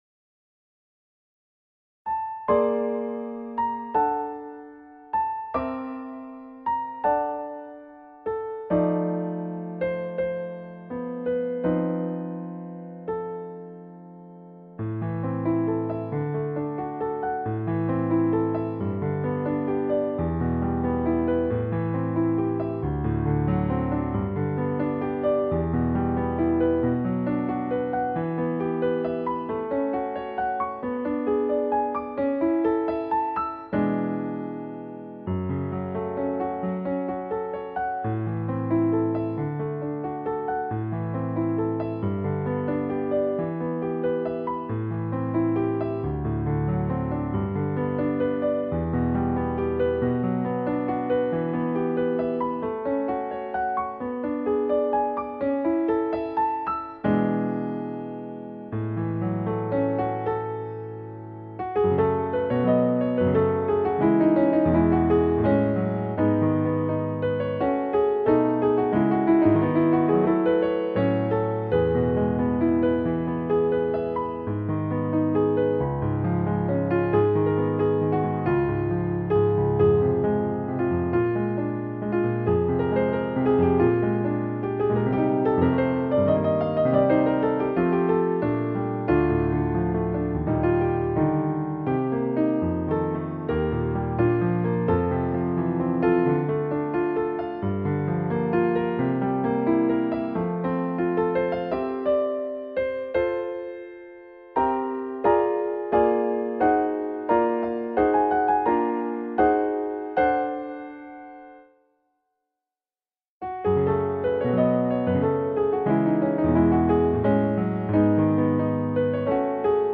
Madrigal piano part